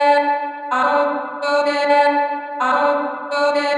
Female vocals Free sound effects and audio clips
• chopped vocals 109-127 female 1 (5) - Dm - 109.wav